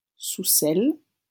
-Soucelles.wav Audio pronunciation file from the Lingua Libre project.